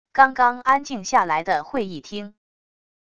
刚刚安静下来的会议厅wav音频